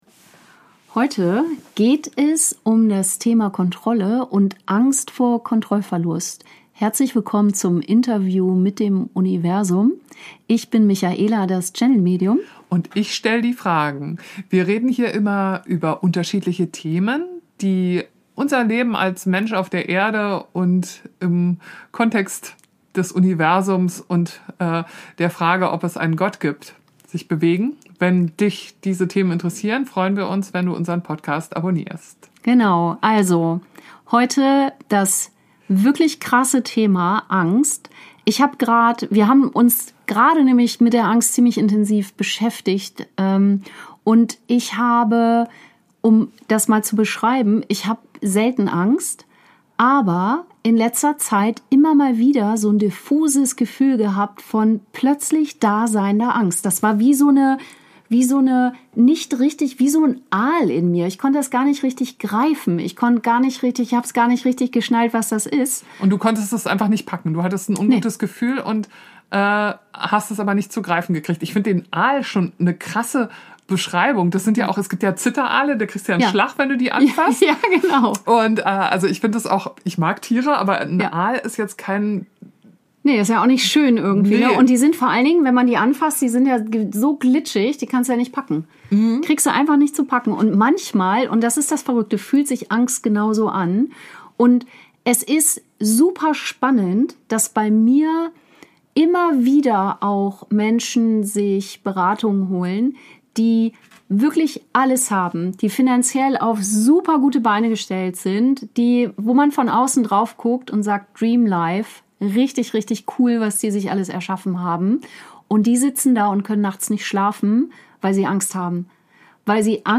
Dazu gibt es eine geführte Tiefenmeditation, die dich direkt in Kontakt mit dem bringt, was hinter deiner Angst steckt.